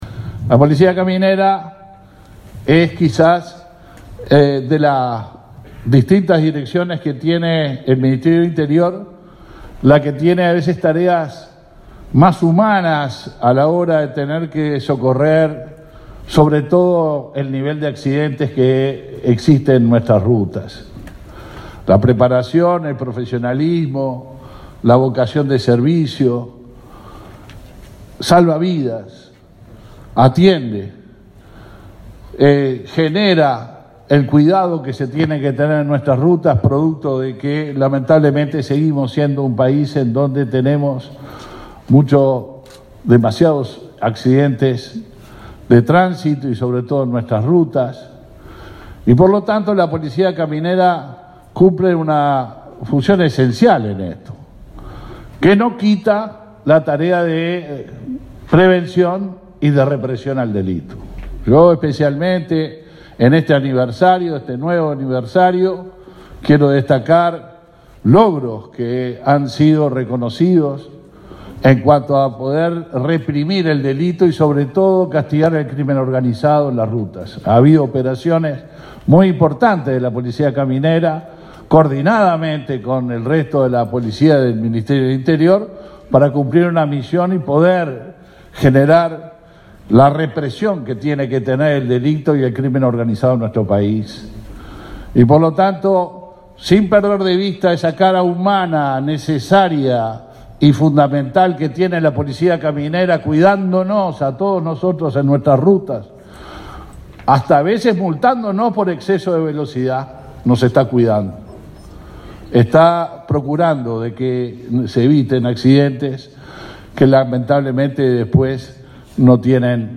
El titular del Ministerio del Interior, Luis Alberto Heber, fue el orador central del acto por el 68.° aniversario de la Policía Caminera.